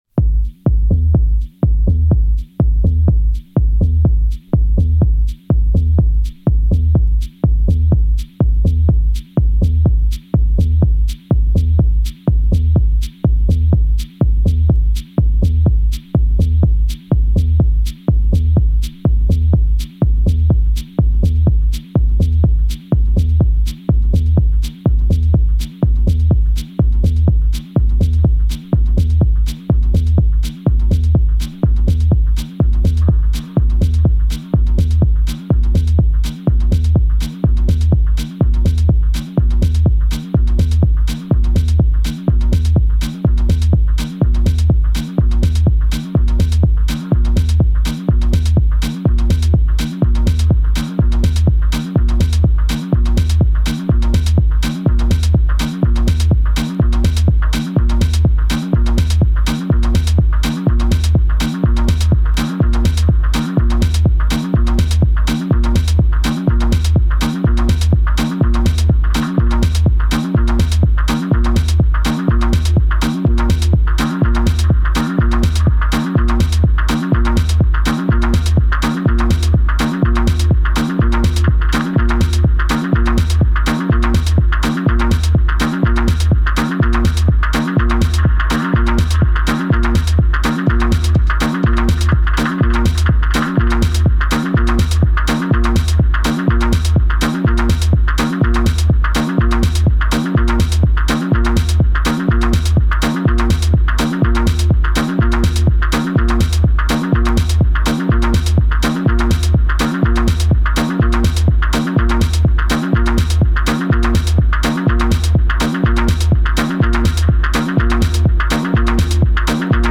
Since I am no techno head by any stretch of the imagination